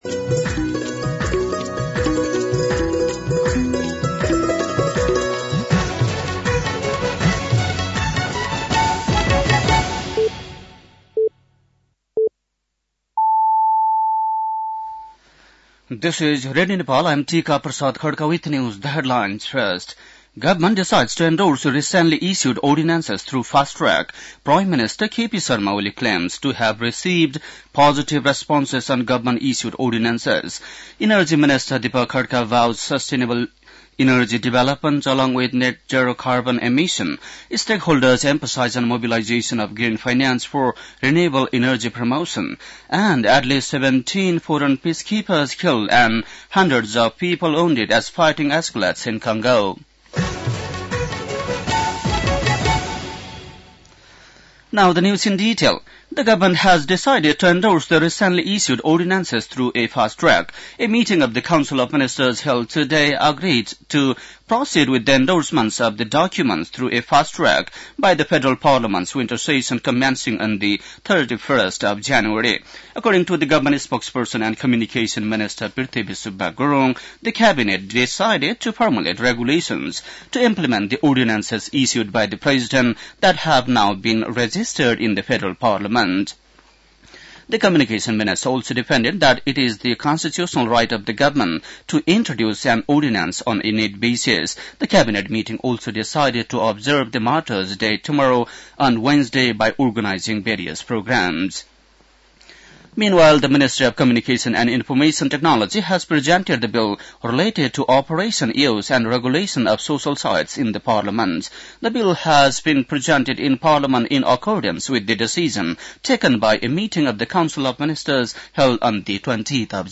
बेलुकी ८ बजेको अङ्ग्रेजी समाचार : १६ माघ , २०८१